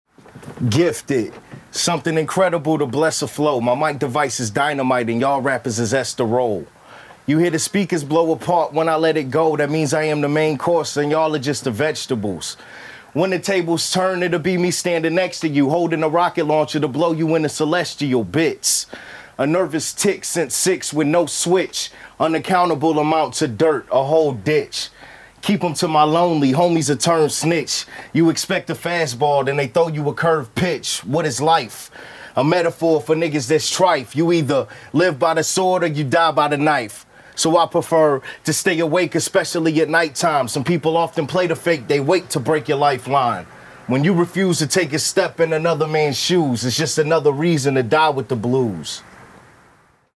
A capella performances are given, we watch with smiles.